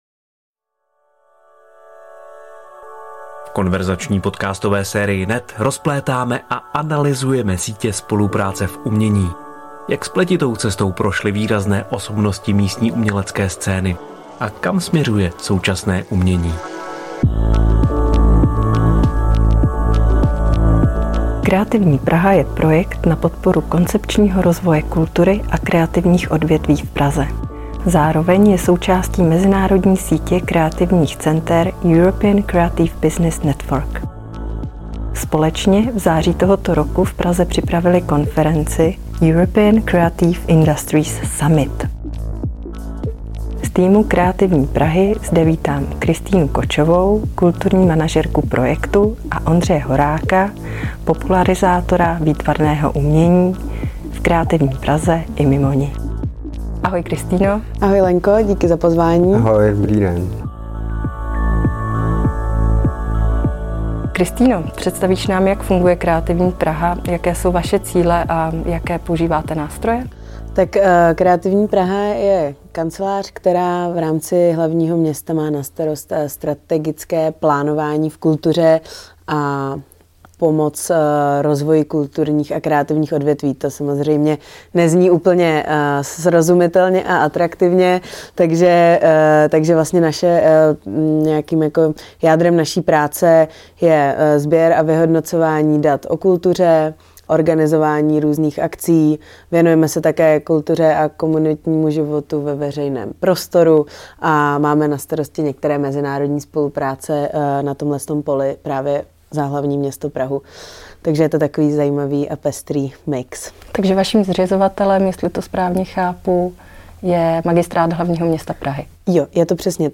Kreativní Praha je také úzce navázána na mezinárodní síť kreativních center European Creative Business Network a díky tomu se na podzim 2022 v Praze sjeli odborníci z mnoha odvětví kreativních průmyslů na konferenci ECIS, jejíž hlavní teze v rozhovoru artikulujeme.